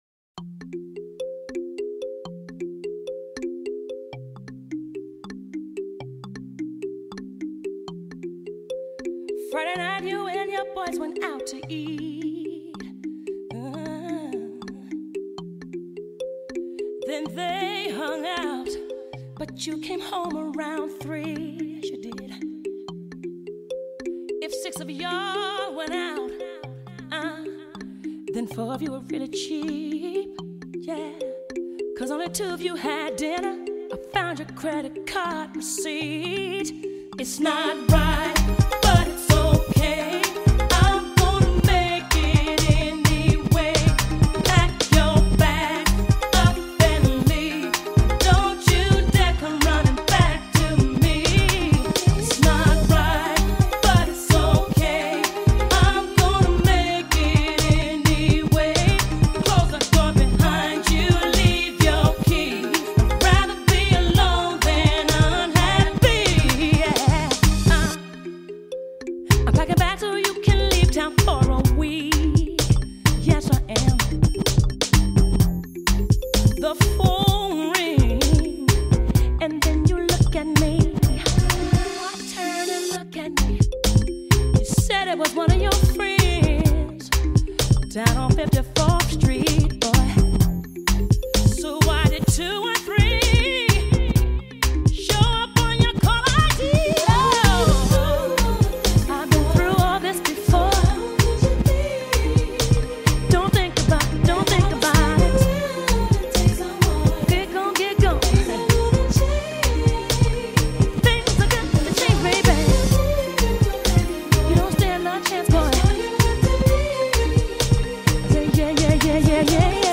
R&B, Pop